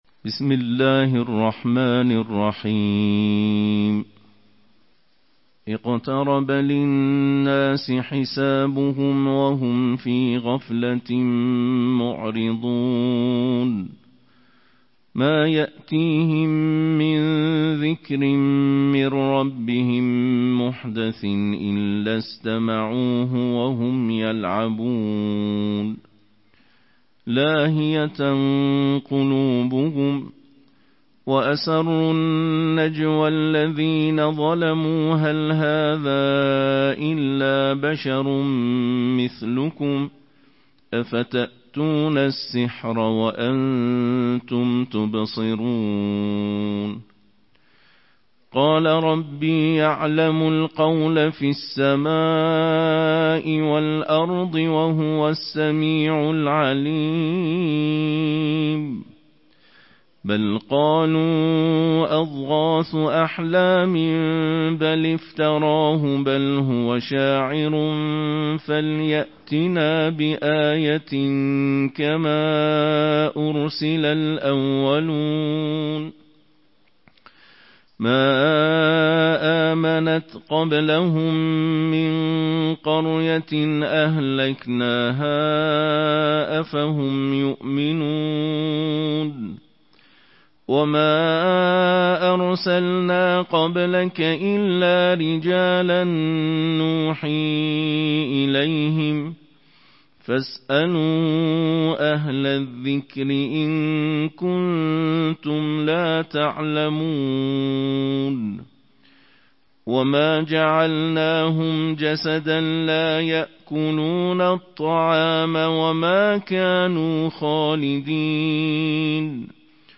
Beynəlxalq qarilərin səsi ilə Quranın on yeddinci cüzünün qiraəti
Quranın on yeddinci cüzünün qiraəti